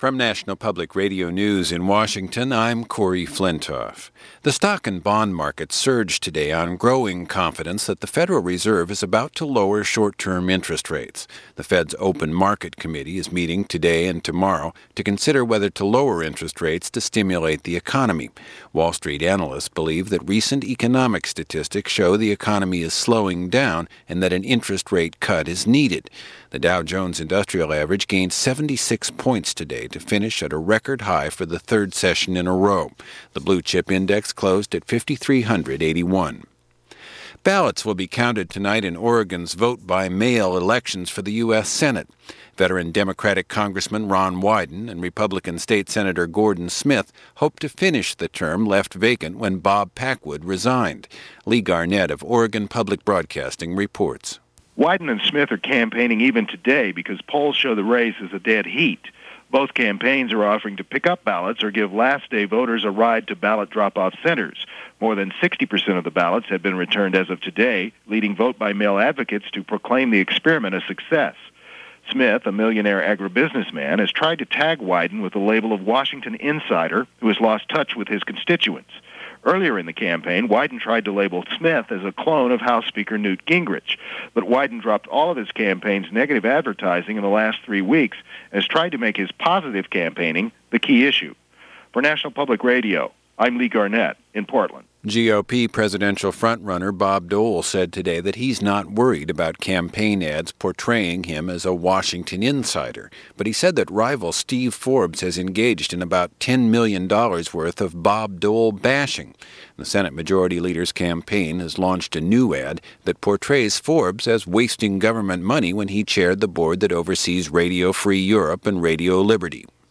NPR Hourly News
All that, and much more for this January 30, 1996 as presented by National Public Radio News.